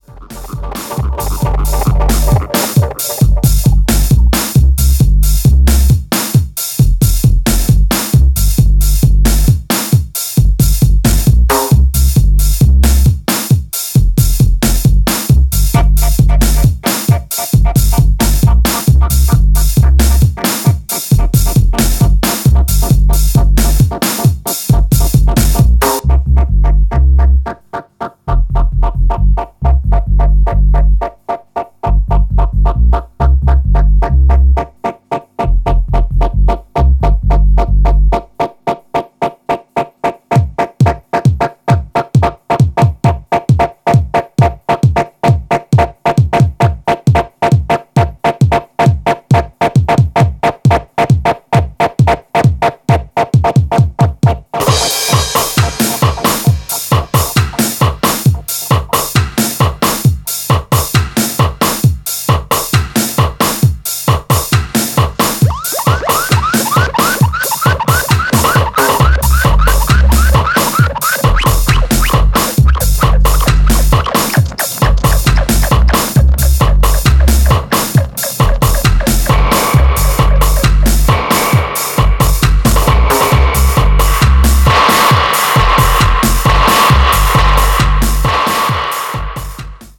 アッパーな134BPMステッパーズ
どちらもアナログでラフ&タフな極太音響、ハウス的にもバッチリ使える四つ打ちで最高のナンバーです。